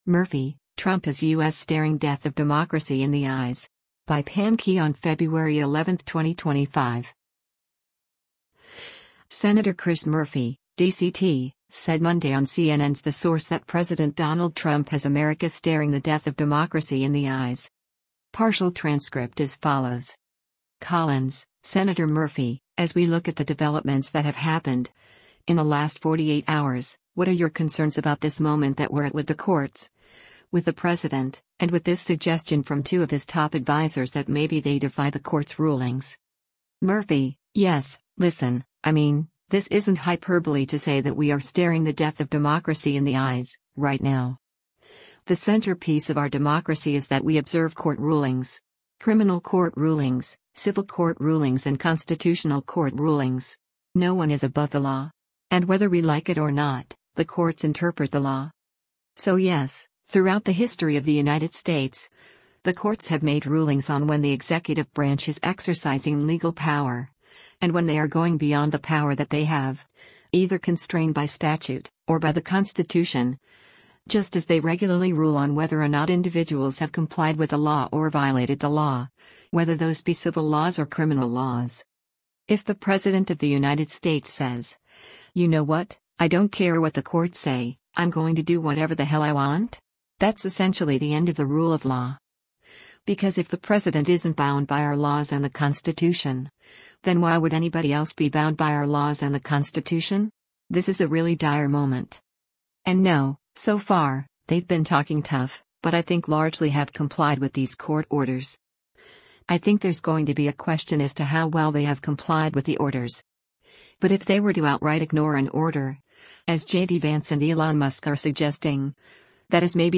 Senator Chris Murphy (D-CT) said Monday on CNN’s “The Source” that President Donald Trump has America “staring the death of democracy in the eyes.”